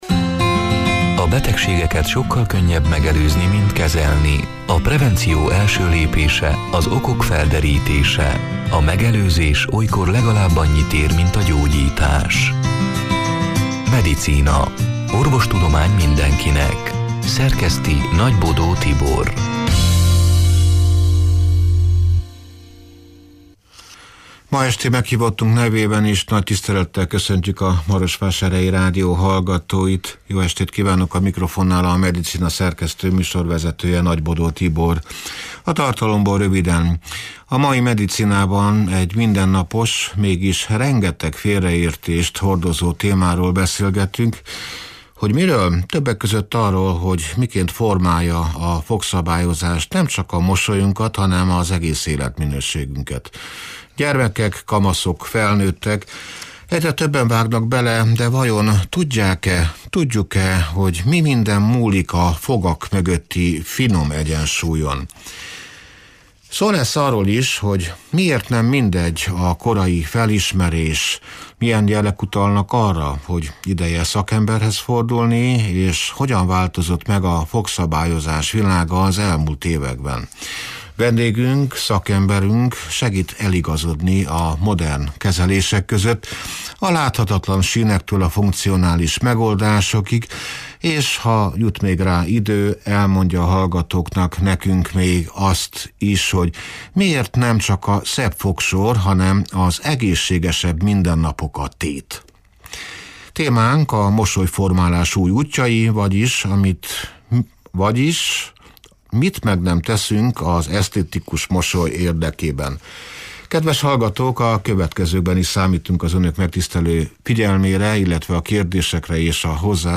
(elhangzott: 2025. december 3-án, szerda este nyolc órától élőben)